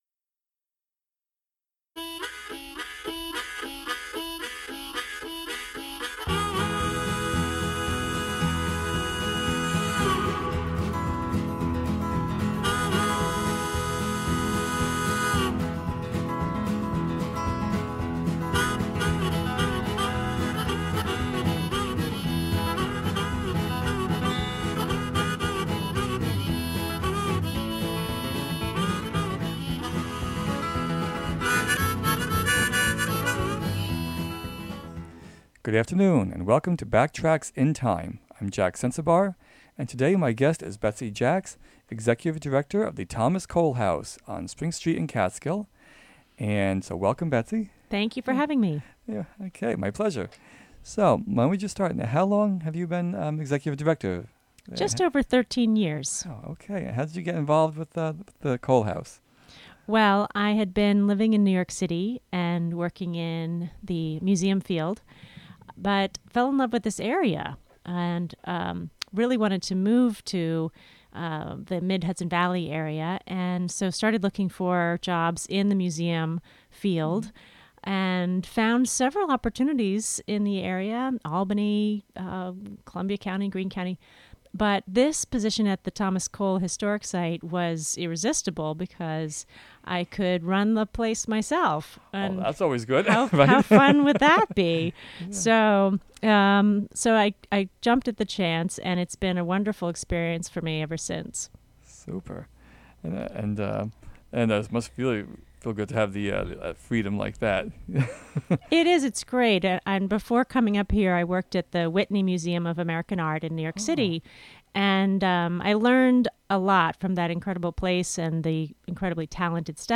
broadcasts